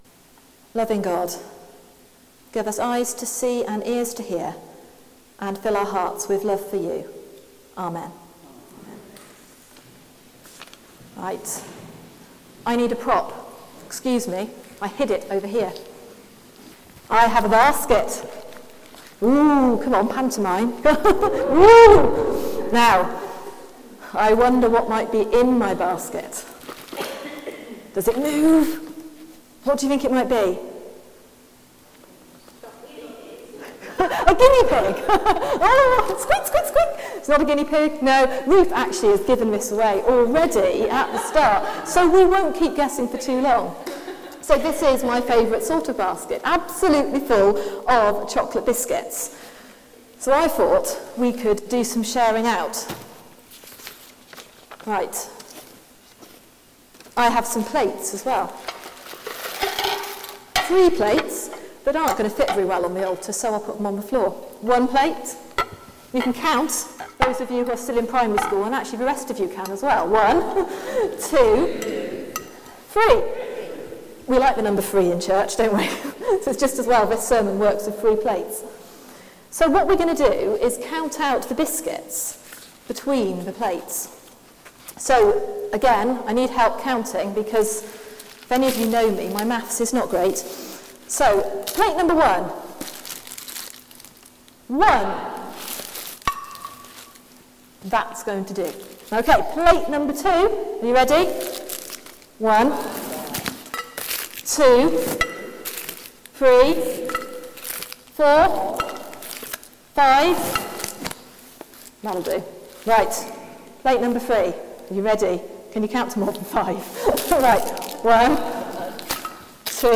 Sermon: The parable of the rich fool | St Paul + St Stephen Gloucester